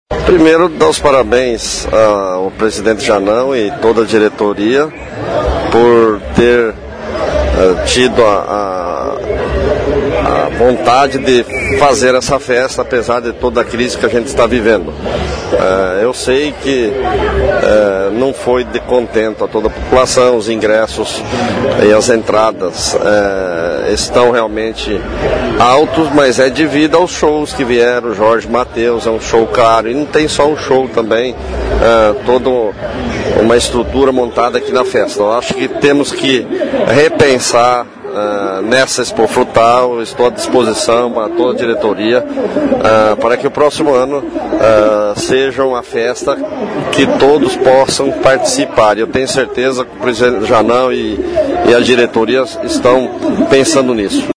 O Prefeito Mauri José Alves fez uma avaliação da 45ª Expo-Frutal.